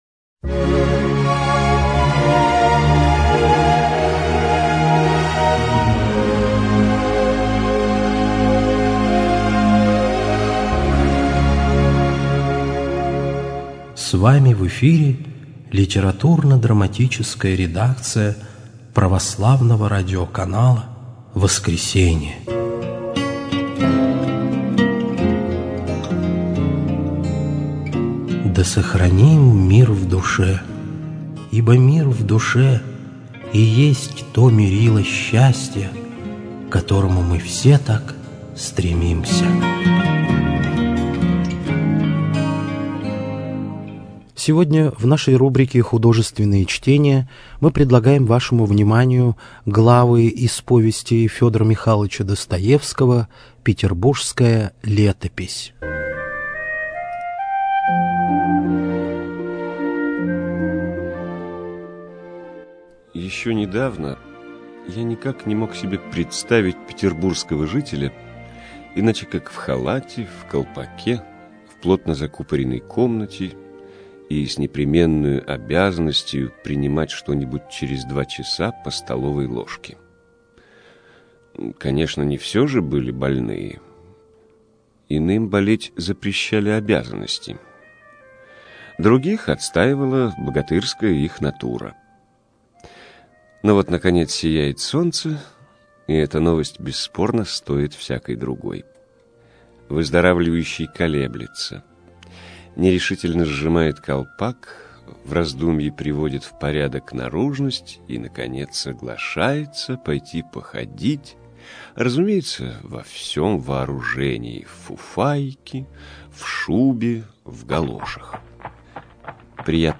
Литдрама | Православное радио «Воскресение»